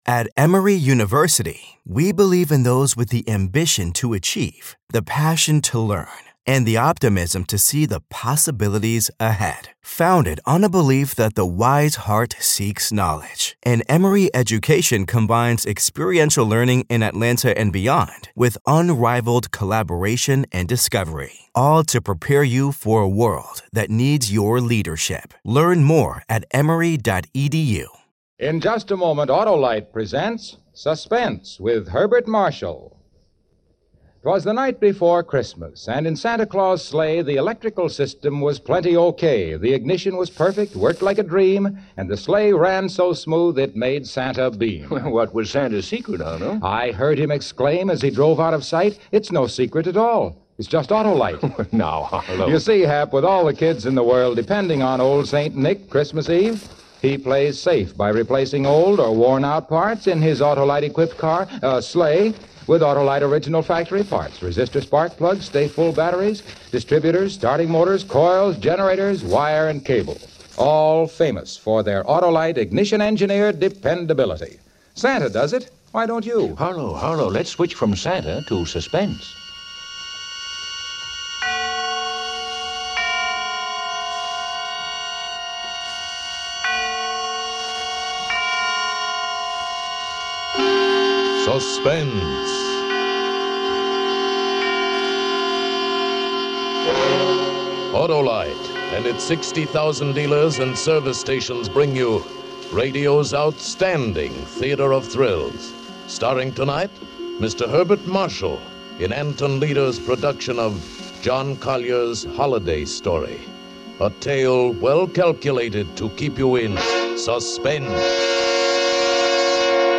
On this week's episode of the Old Time Radiocast, we present you with two stories from the classic radio program Suspense!